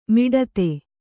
♪ miḍate